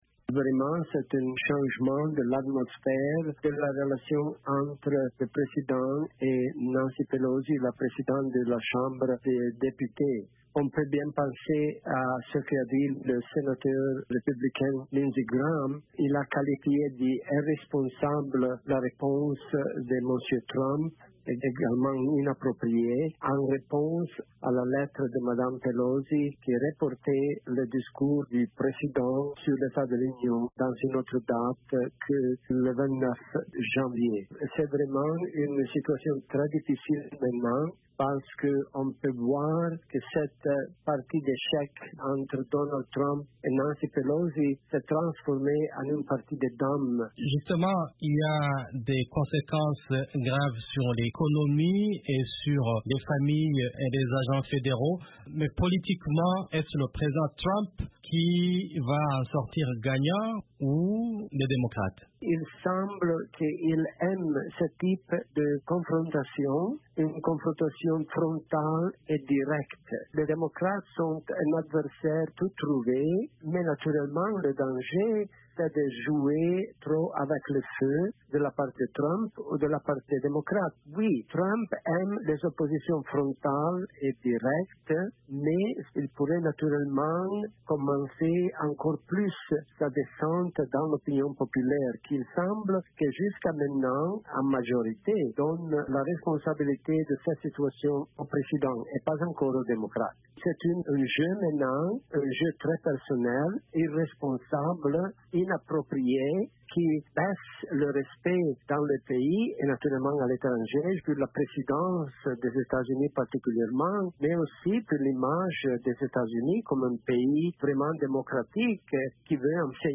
l’analyste politique